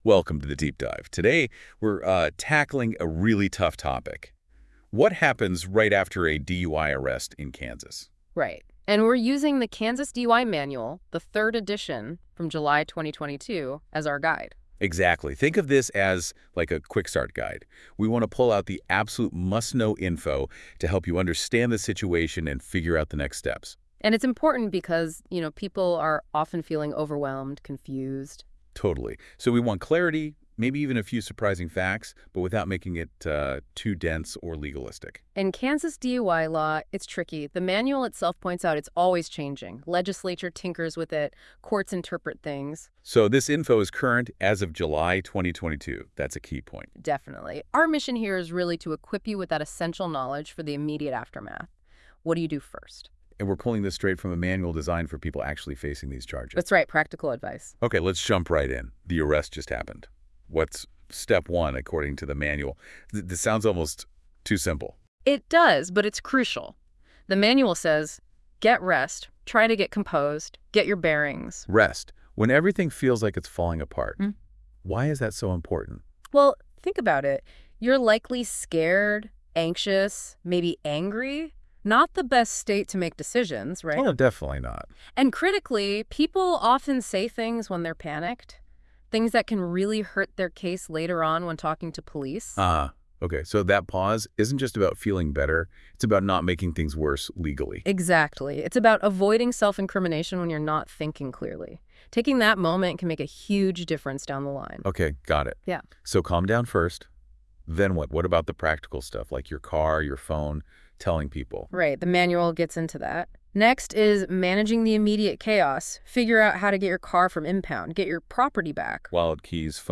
Using AI technology, the software took the Kansas DUI Manual and made a podcast out of it that is actually amazing!